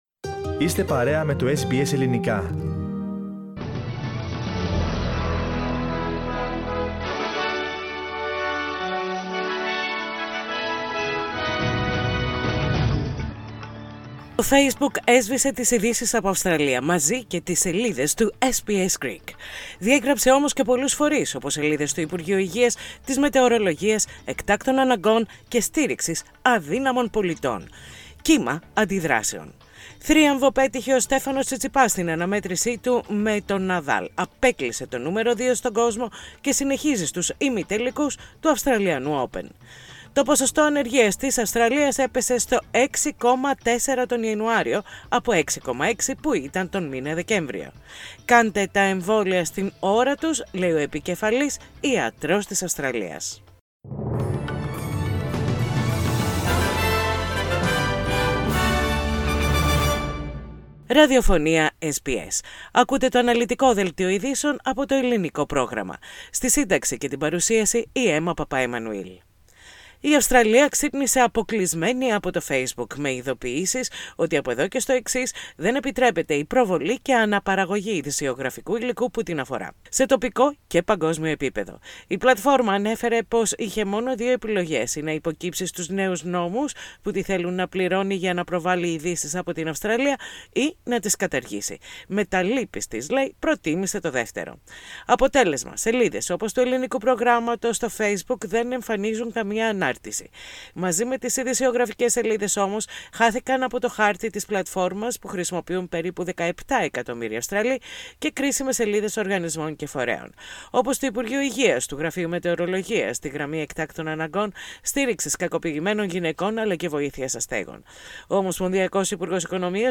Δελτίο ειδήσεων - Πέμπτη 18.2.21